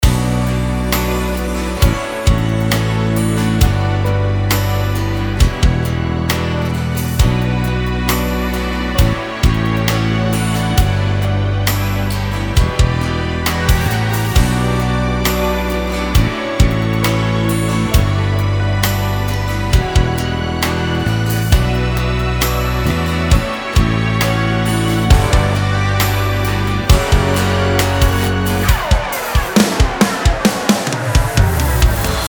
Как то я уже писал и спрашивал у Господ, литературу по акустике звука и пространству. Изучив ее, применил в этой аранжировке.